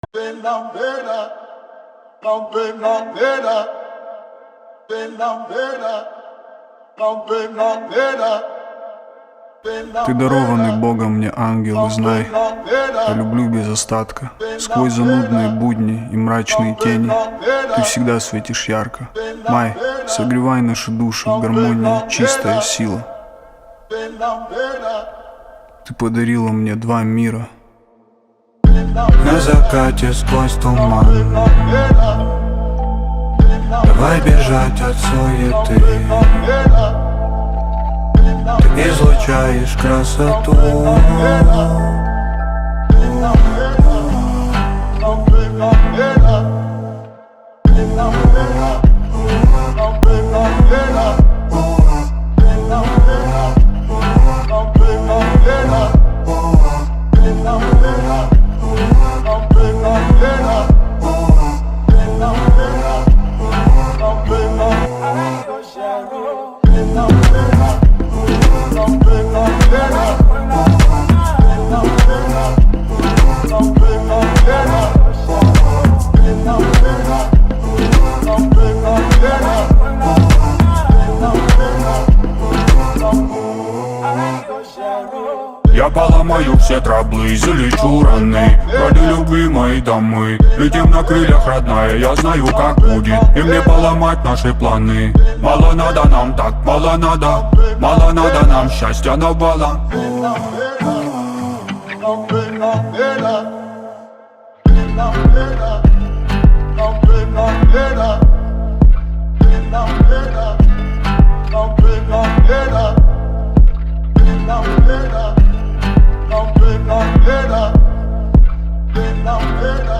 Жанр: Русские